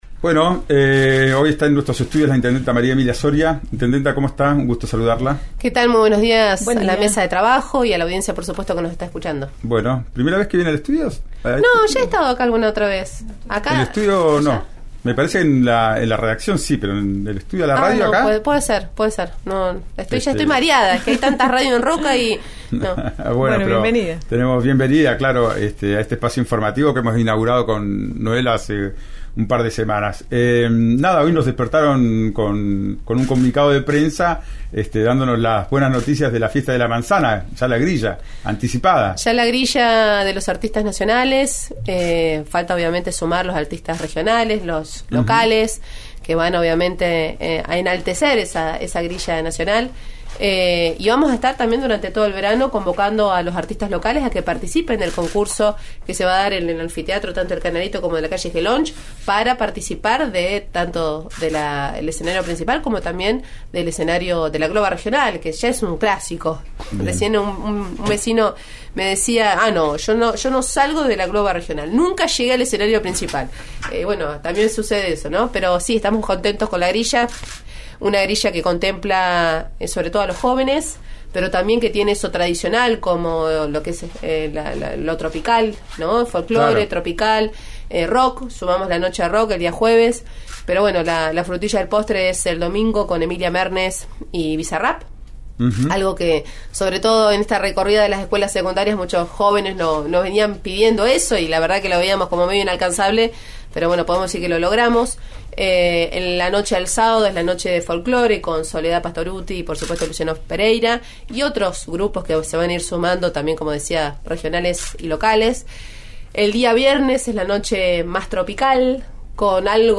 Soria habló esta mañana en el programa Ya es Tiempo que se emite por RN Radio y aseguró que el panorama resulta poco alentador en cuanto a la obtención del sello para presentar candidatos una plataforma que responda al PJ tanto en Río Negro como a nivel local.